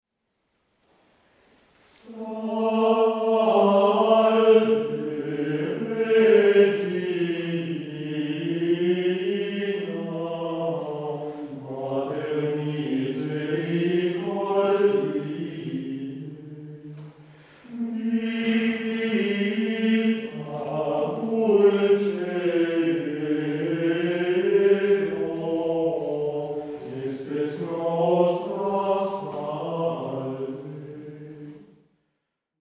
canto gregoriano
Salve Regina - tono solenne antifona